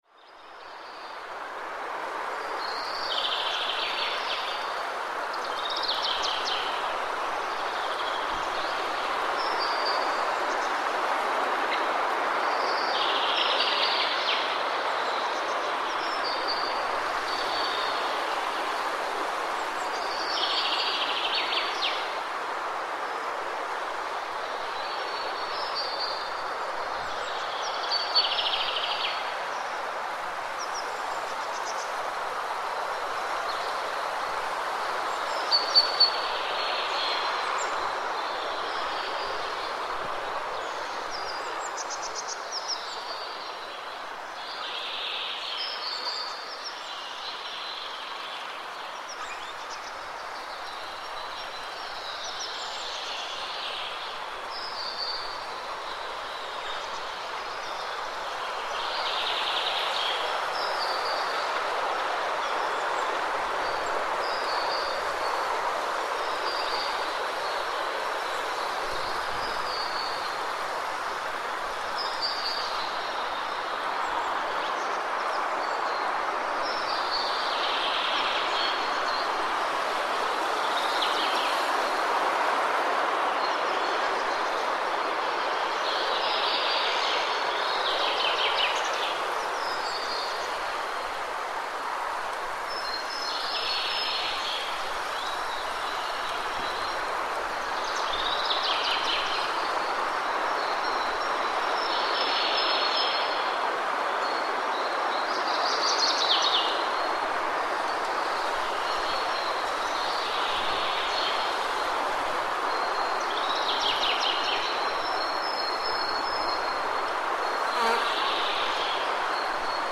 Wind-and-birds-sound-effect.mp3